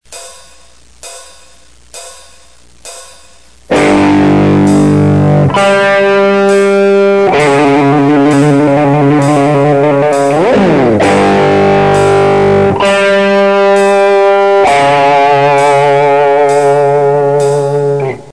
Вместо вибрато на ноте ре я делаю глиссандо к ми и обратно (ПРИМЕР 3).